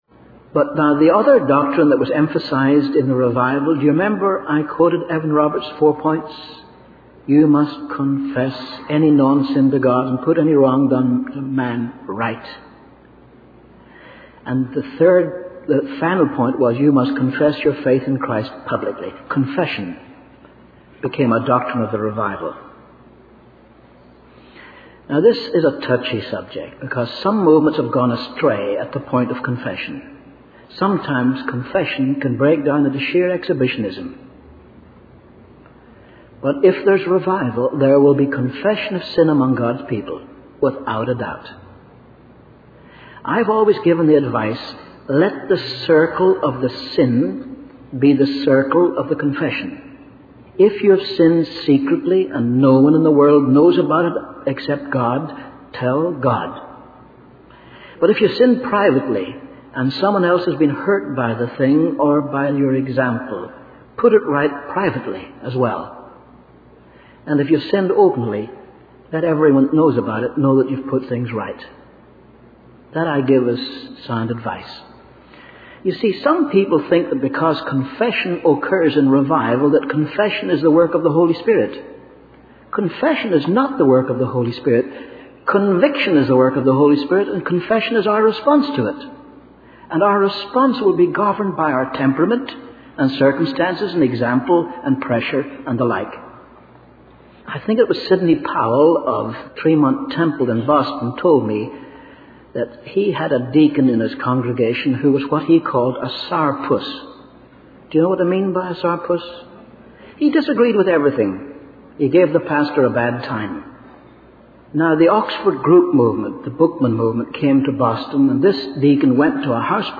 In this sermon, the preacher discusses the importance of confession in the context of revival.